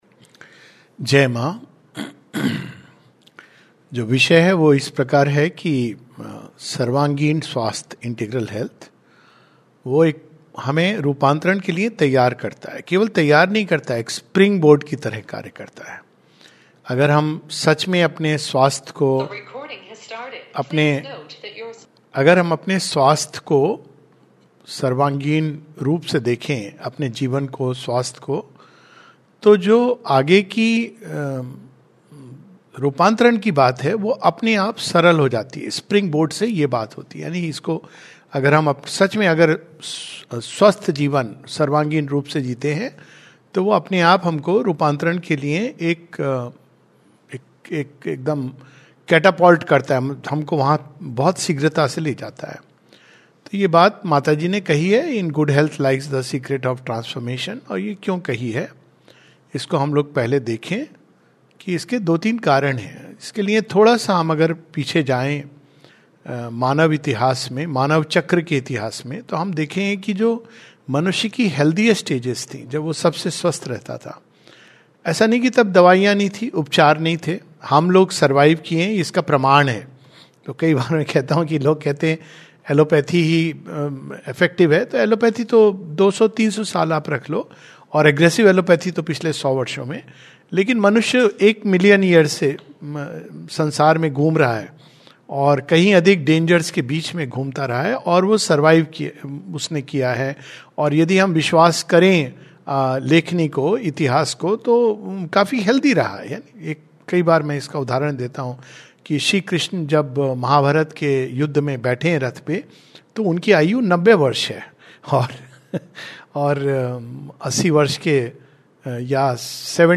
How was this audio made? [Integral Health and Transformation] This is a webinar with the Sri Aurobindo Medical Association conference, Odisha chapter.